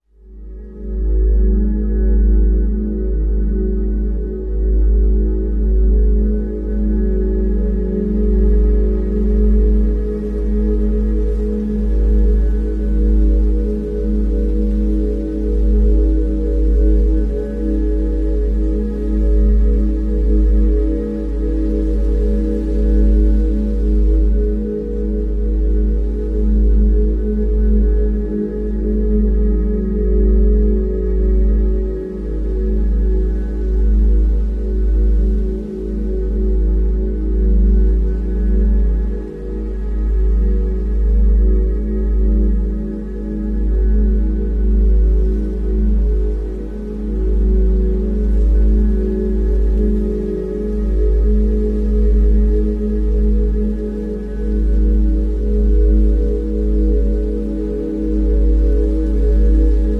417Hz Solfeggio Frequency: remove negative